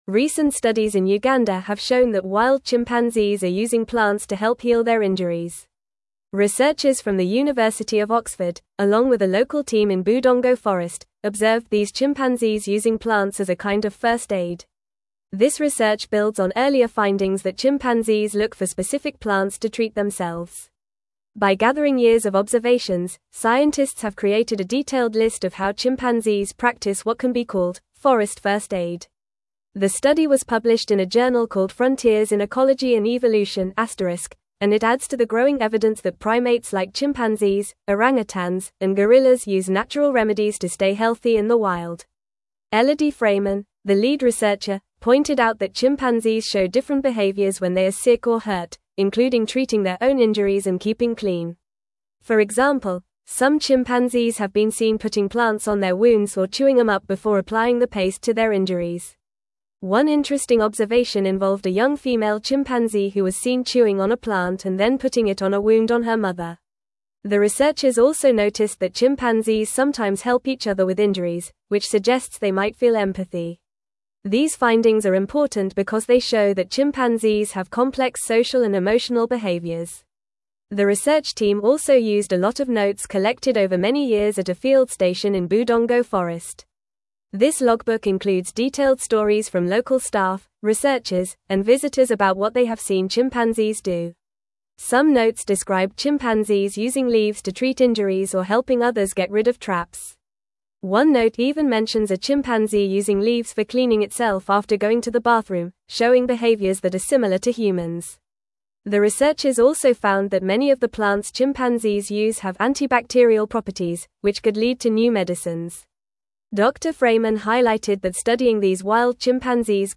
Fast
English-Newsroom-Upper-Intermediate-FAST-Reading-Chimpanzees-Use-Medicinal-Plants-for-Self-Care-in-Uganda.mp3